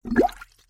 ui_interface_135.wav